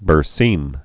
(bər-sēm)